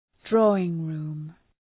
Προφορά
{‘drɔ:ıŋ,ru:m}